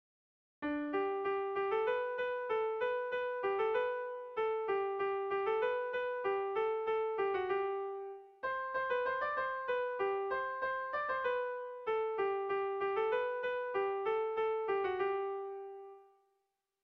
Irrizkoa
Zortziko txikia (hg) / Lau puntuko txikia (ip)
ABDB